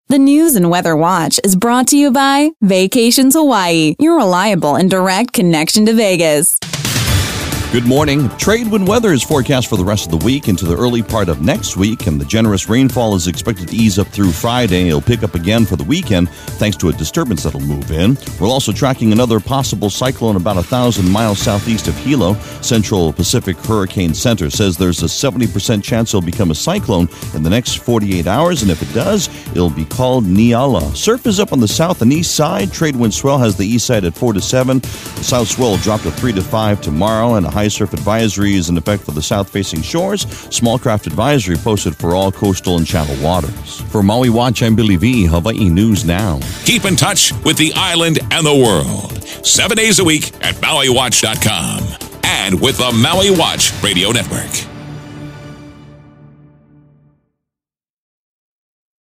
Your daily weather brief for September 22